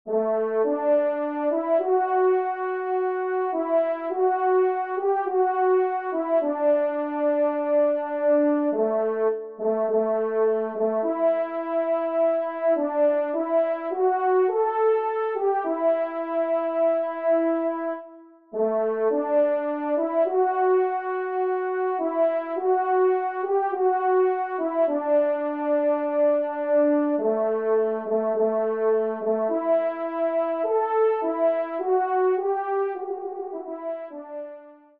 Genre :  Divertissement pour Trompes ou Cors
1ère Trompe